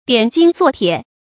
點金作鐵 注音： ㄉㄧㄢˇ ㄐㄧㄣ ㄗㄨㄛˋ ㄊㄧㄝ ˇ 讀音讀法： 意思解釋： 見「點金成鐵」。